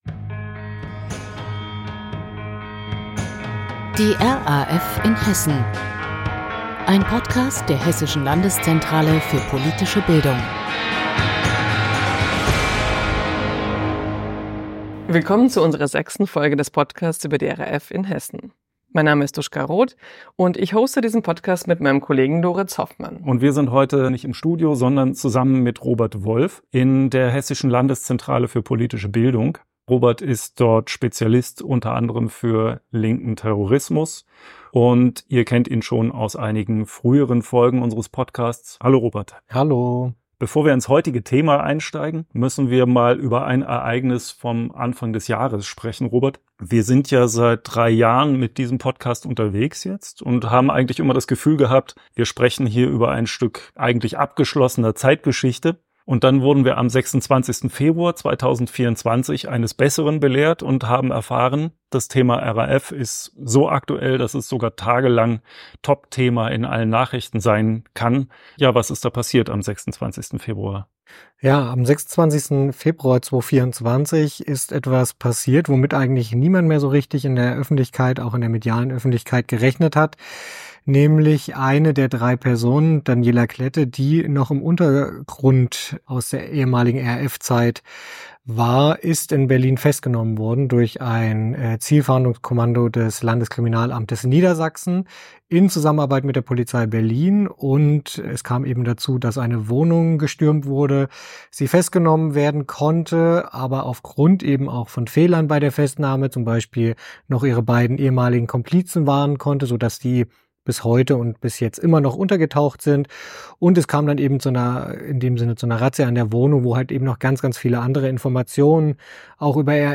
Moderation
Interviewpartner